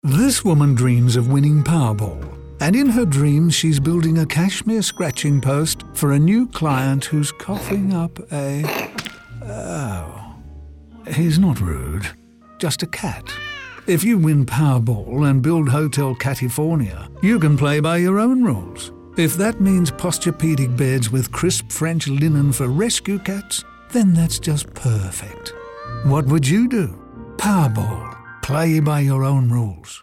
In radio, we brought to life a dream of setting up Hotel Catifornia – a hotel for rescue cats, not told in a boring way, but in an entertaining way that’s true to the brand. We used the power of audio to let people imagine this life for themselves and inspire them during one of the least inspiring times.